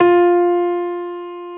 55p-pno22-F3.wav